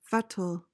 PRONUNCIATION: (FET-l) MEANING: noun: State or condition.